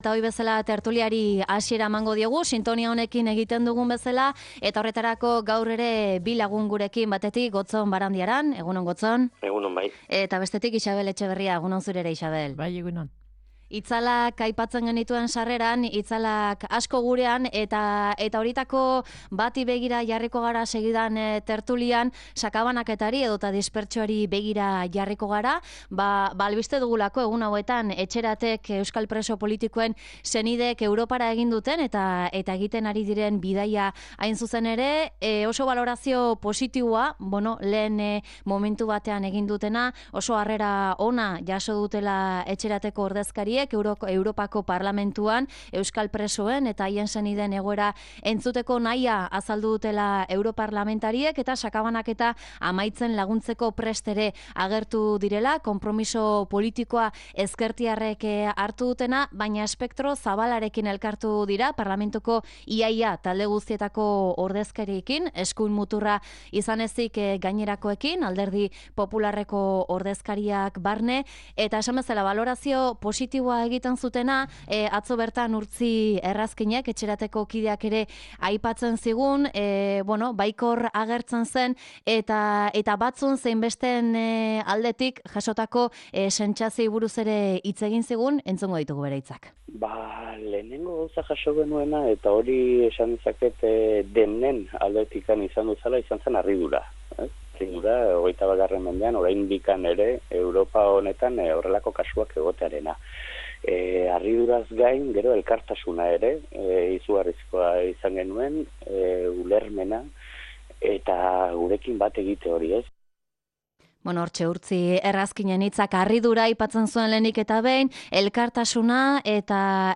Tertulia: Dispertsioarekin amaitzeko neurriak Europan, instituzioetan, herritarren artean